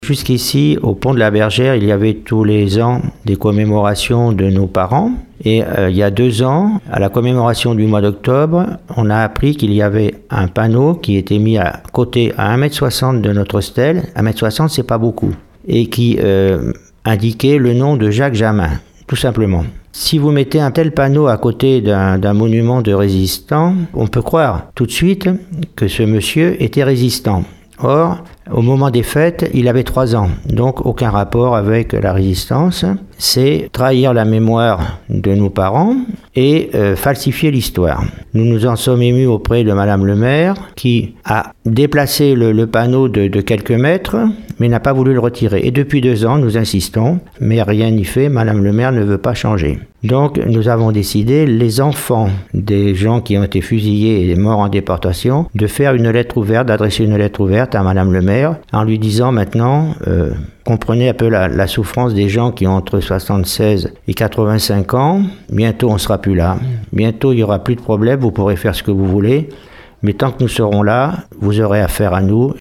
fils de résistant